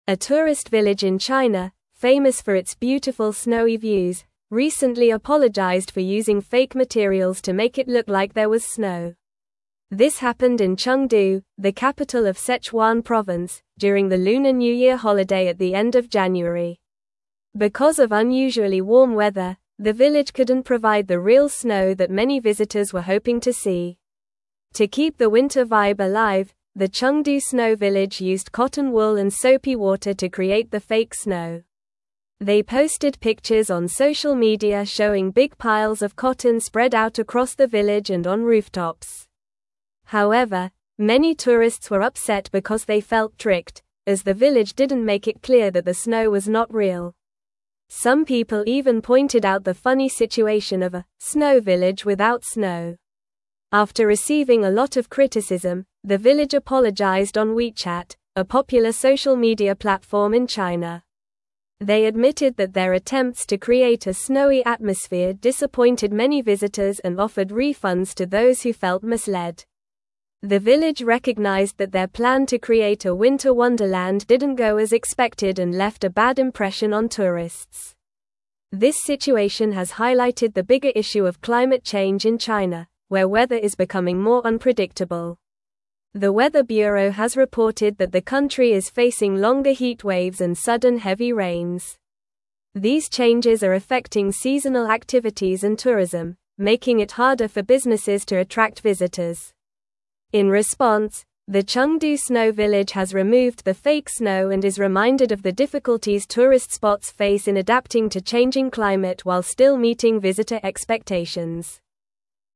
Normal
English-Newsroom-Upper-Intermediate-NORMAL-Reading-Chengdu-Village-Apologizes-for-Fake-Snow-Misleading-Tourists.mp3